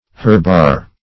herbar - definition of herbar - synonyms, pronunciation, spelling from Free Dictionary Search Result for " herbar" : The Collaborative International Dictionary of English v.0.48: Herbar \Herb"ar\, n. An herb.